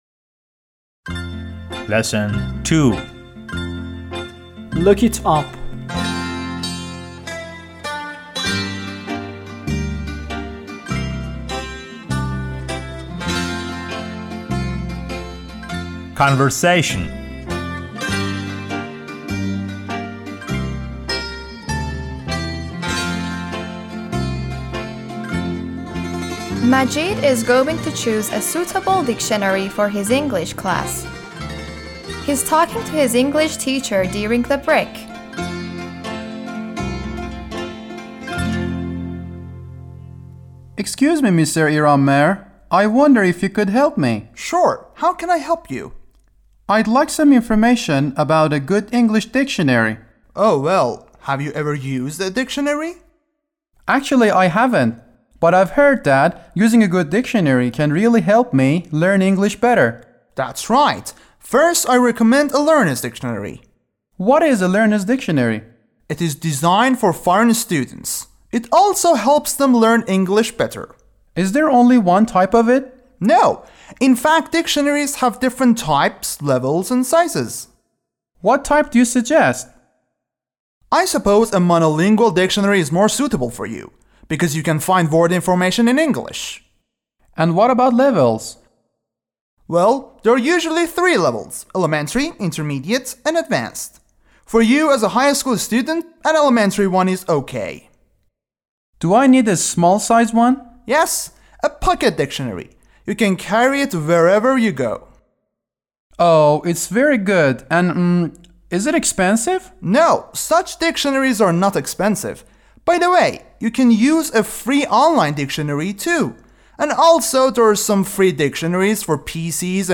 12-L2-Conversation.mp3